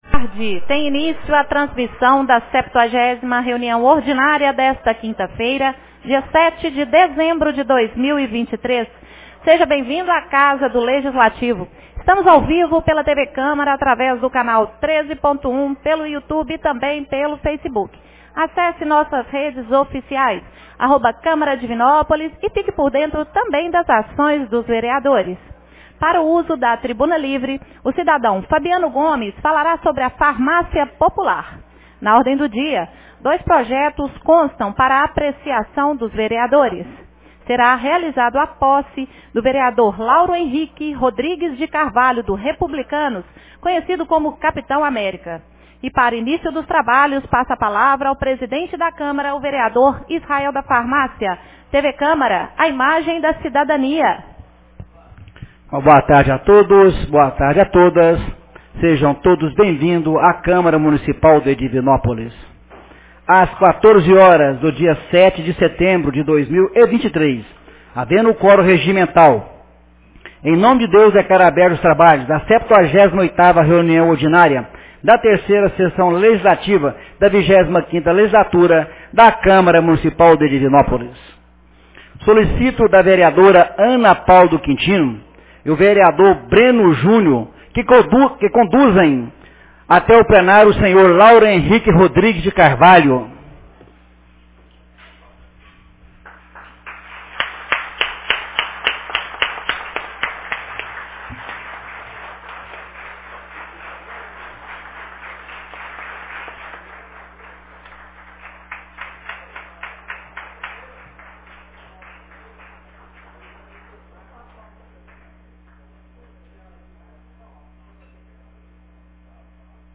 78ª Reunião Ordinária 07 de dezembro de 2023